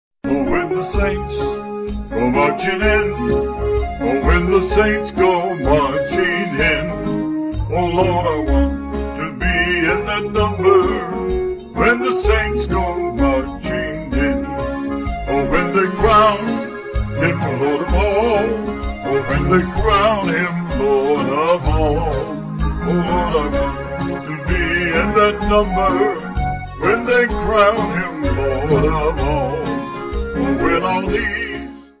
Vocals & Band